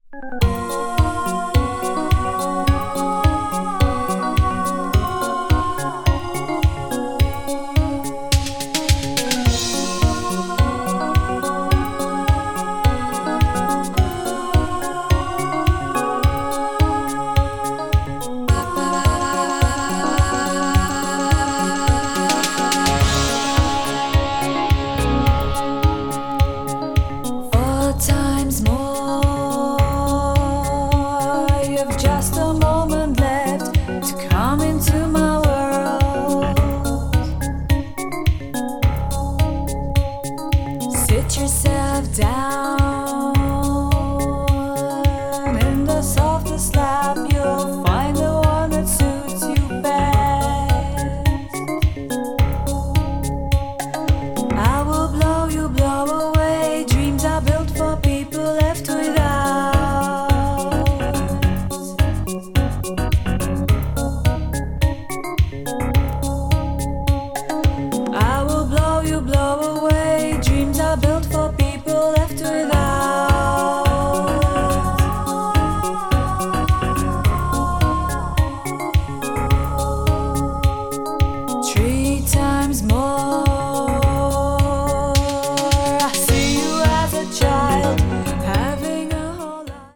さらにカラフルで爽快感のあるサウンドに昇華された作品で
belgian pop   leftfield   new wave   synth pop